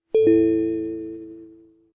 QQ消息提示音
标签： QQ 提示音 消息 聊天
声道单声道